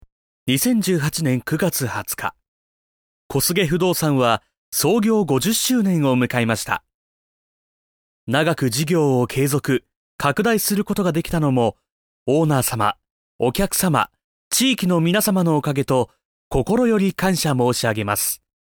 Male
20s, 30s, 40s, 50s, 60s
Tokyo standard accent (native)
Microphone: Lewitt LCT 441 FLEX